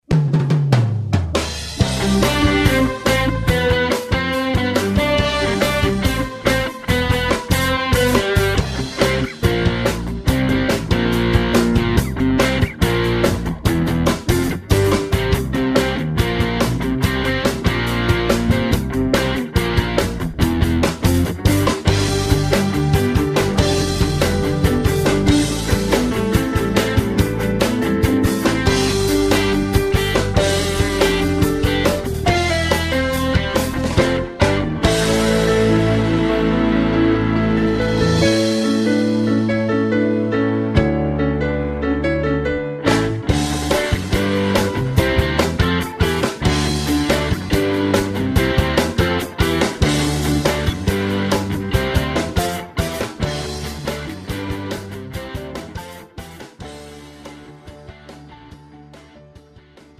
음정 (-1키)
장르 뮤지컬 구분